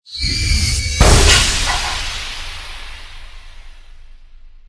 netslam.ogg